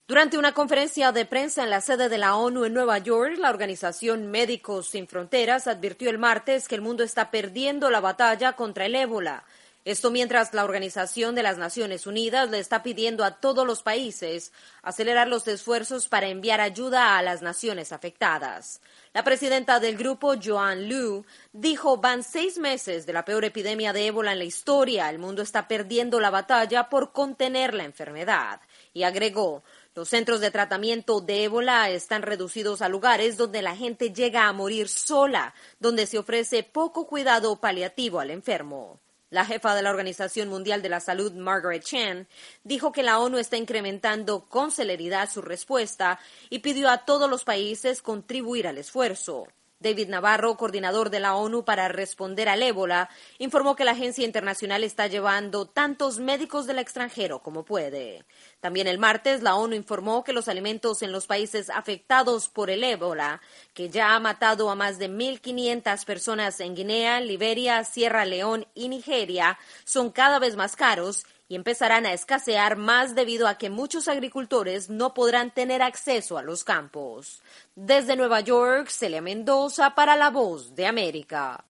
La epidemia del ébola en África amenaza con extenderse rápidamente, mientras la Organización Mundial de la Salud y la ONU tratan de llevar los recursos necesarios a las áreas afectadas. Desde Nueva York informa